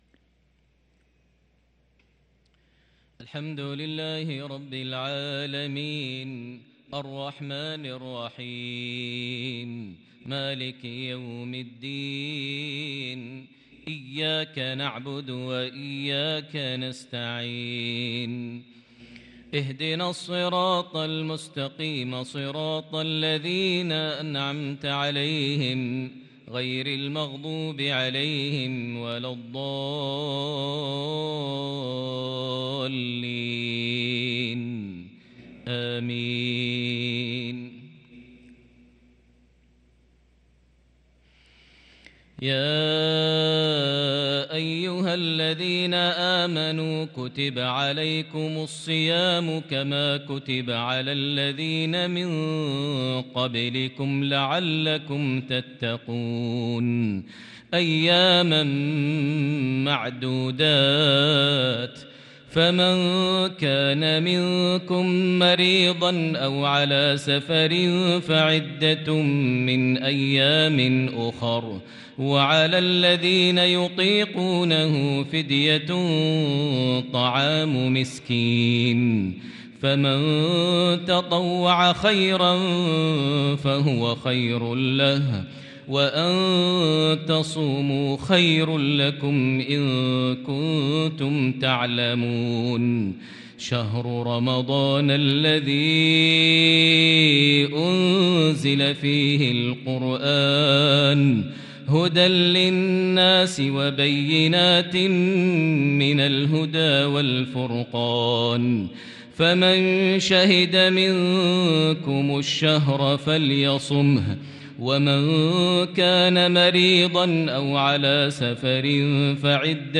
صلاة العشاء للقارئ ياسر الدوسري 23 شعبان 1443 هـ
تِلَاوَات الْحَرَمَيْن .